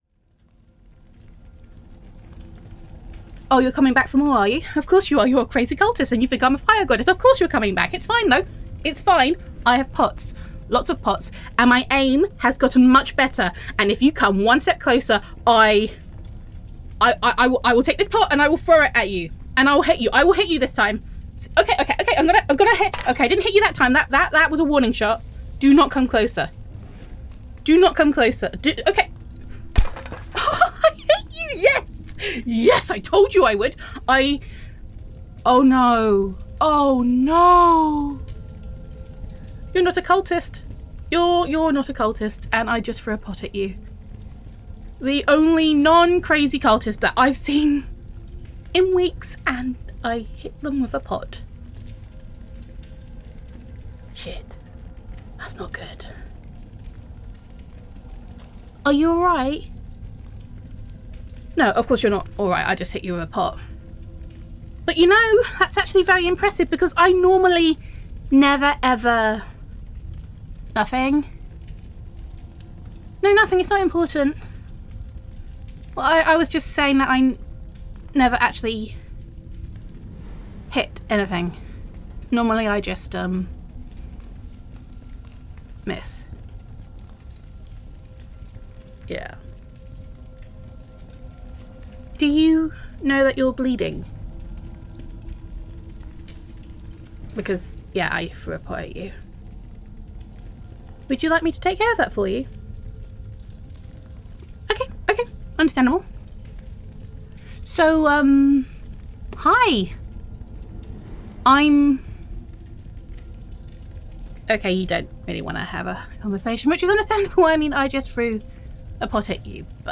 Downloads Download [F4A] Fifteen Minutes of Flame [Exchange Student Roleplay][Fire Away].mp3 Content No, seriously, does this look like the face of a fire goddess to you?
Theme or Scenario: Trapped in a Temple of Fire Emotional Tone or Mood: Hilarious, Chaotic, Slightly Hysterical Content Type: Audio Roleplay